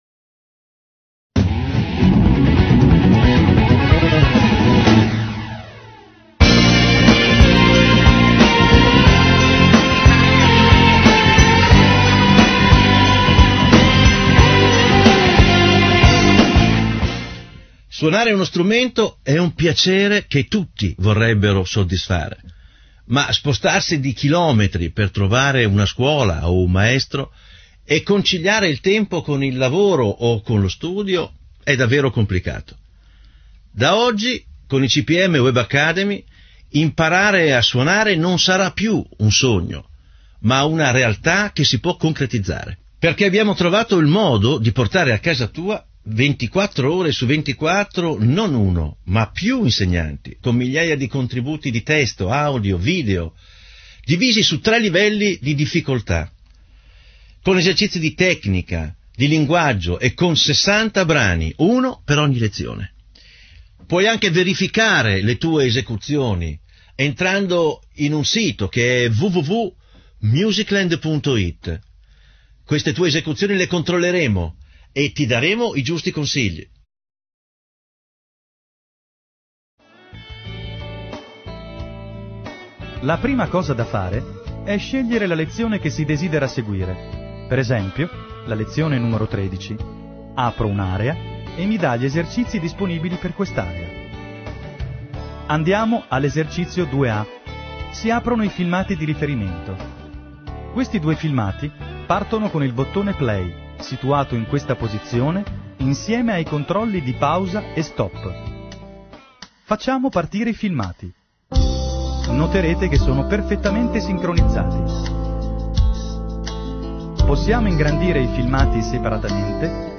Voce ufficiale presentazione e narratore corsi